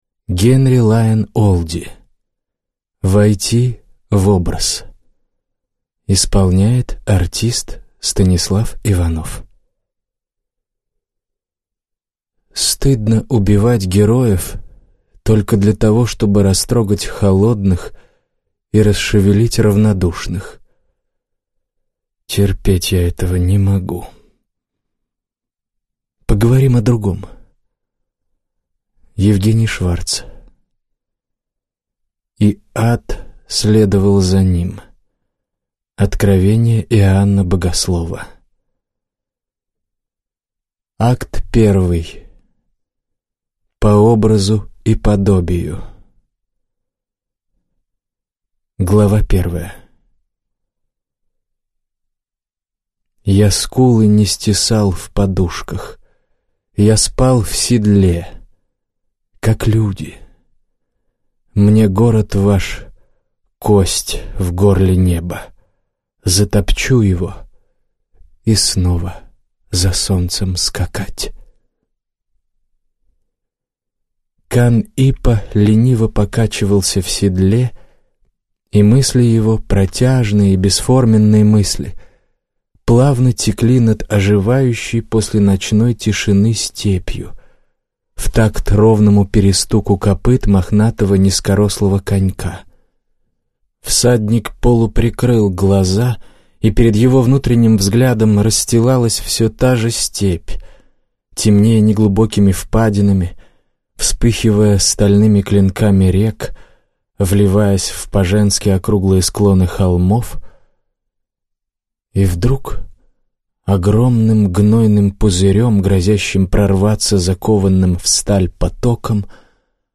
Аудиокнига